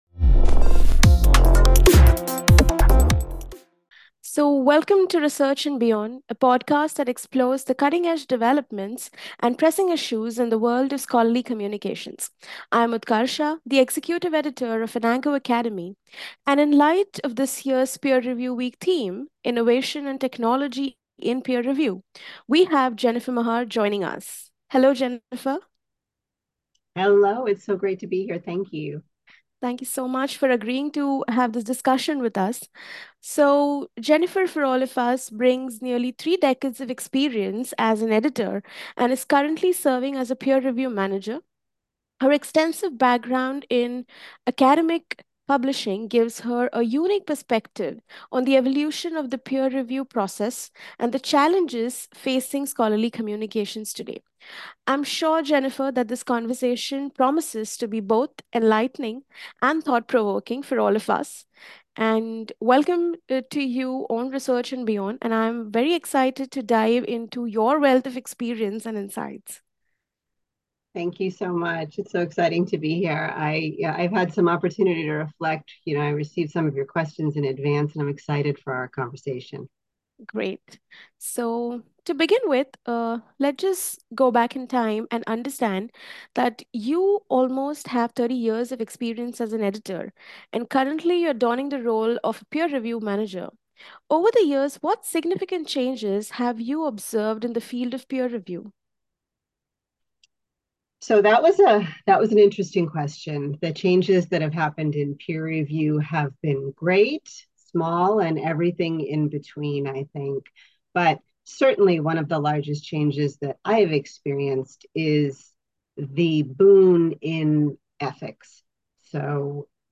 Exploring Innovation in Peer Review: A Conversation